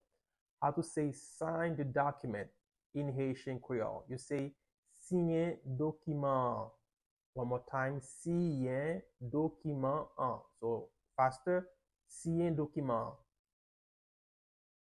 Pronunciation:
17.How-to-say-Sign-The-Document-in-Haitian-Creole-–-Siyen-dokiman-an-.mp3